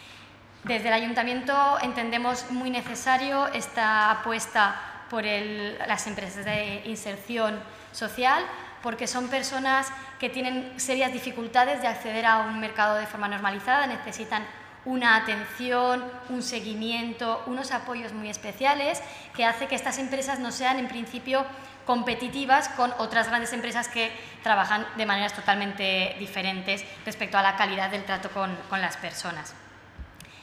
corteconcejal1.wav